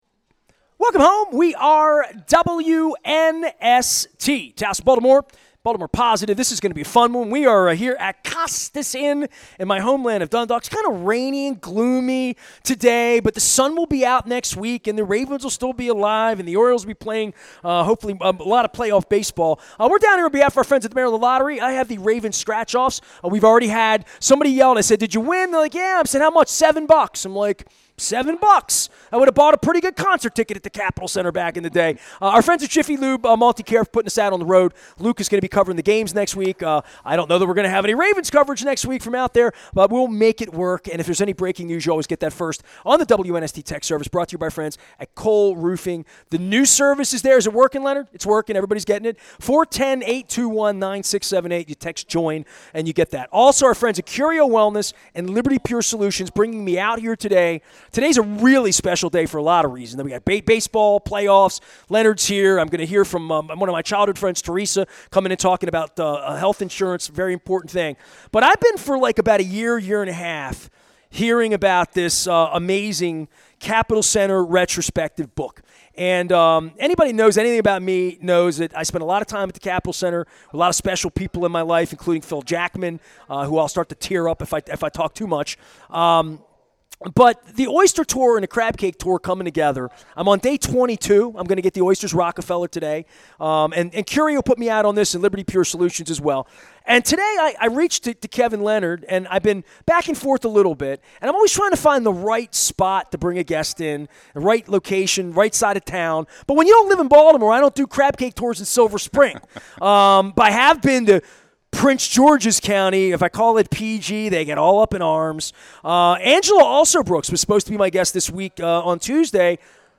from Costas Inn on the Maryland Crab Cake Tour. Everything you ever wanted to know about your favorite red and blue arena on the D.C. beltway where all the stars shined.